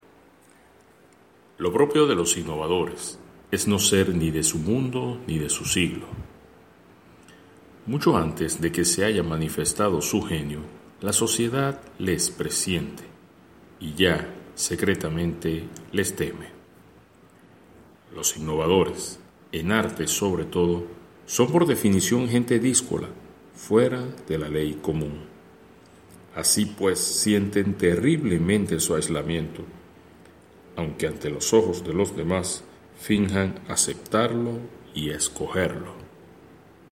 Voz fuerte y clara.
Sprechprobe: Sonstiges (Muttersprache):